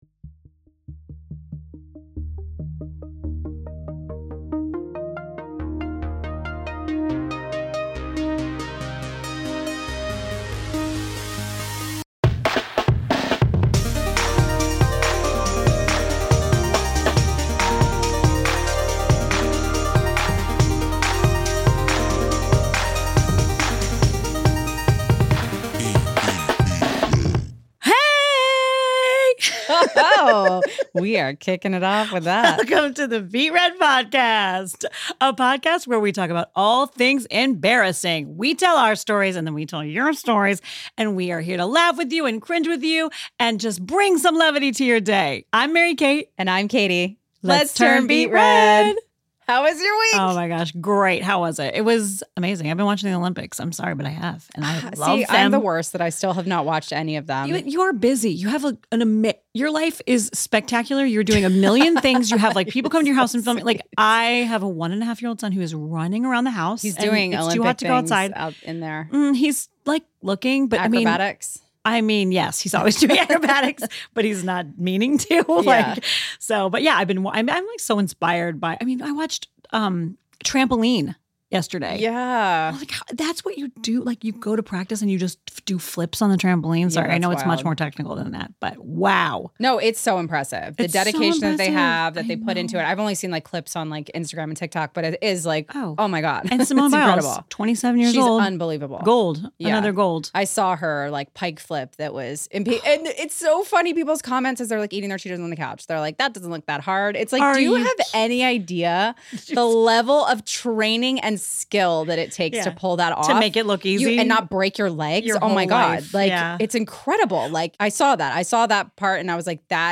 at PROJKT studios in Monterey Park, CA.